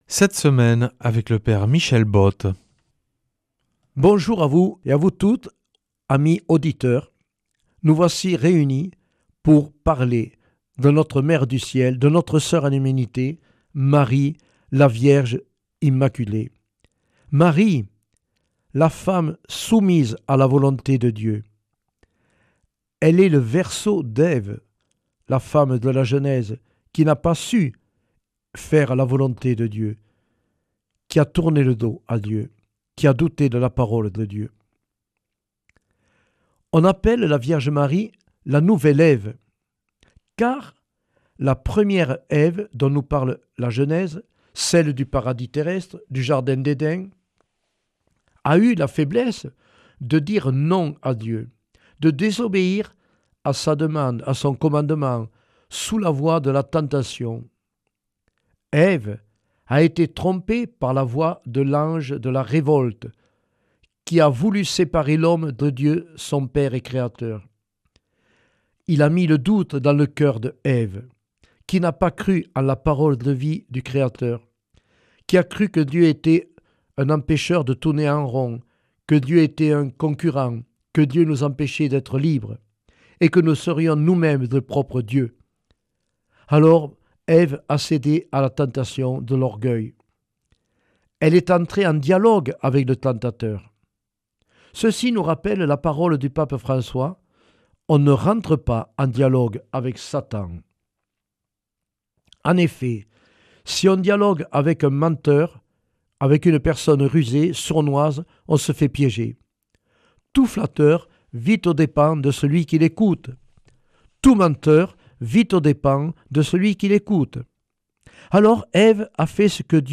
mercredi 8 octobre 2025 Enseignement Marial Durée 10 min